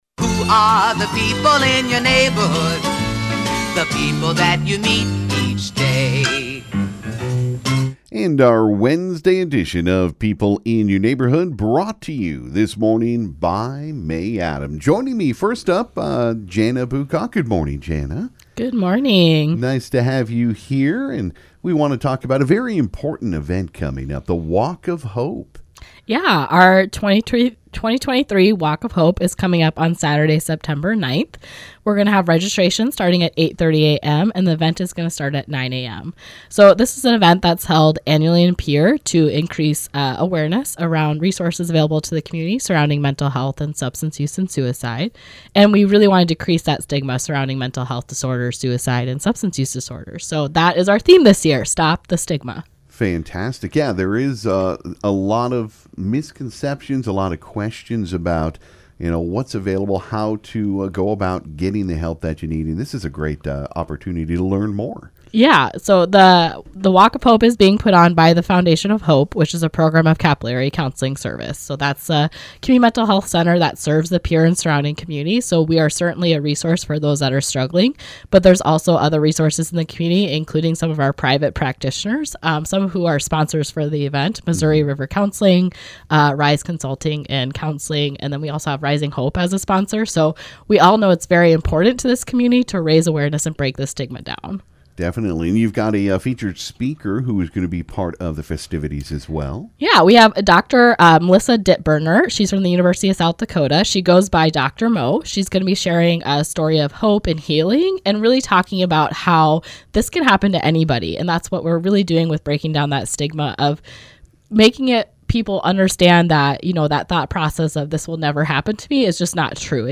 This morning on People In Your Neighborhood we welcomed a pair of guests to talk about a couple of great events.